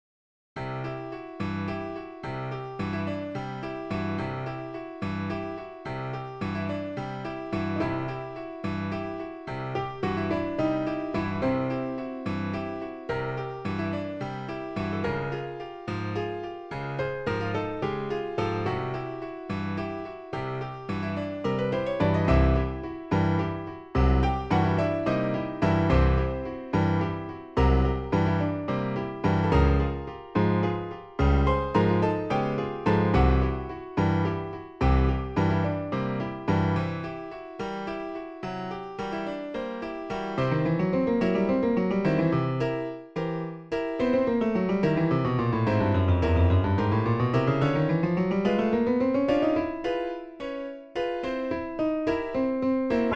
Besetzung 2 Klaviere